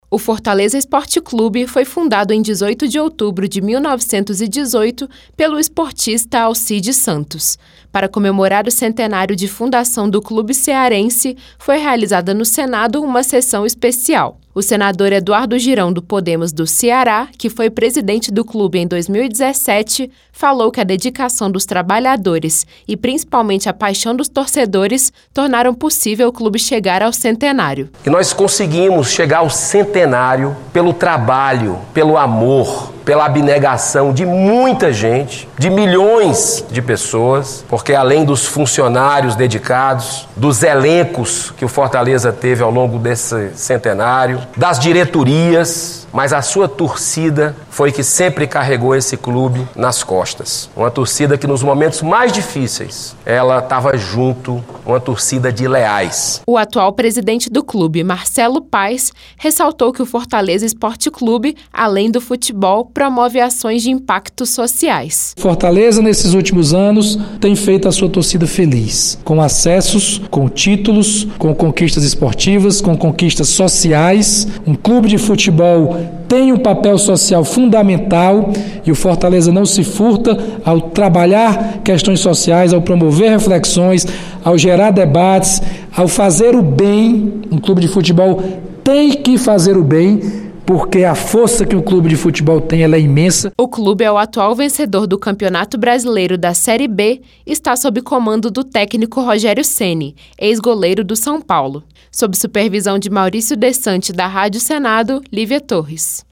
Sessão especial do Senado celebrou o centenário de criação do Fortaleza Esporte Clube.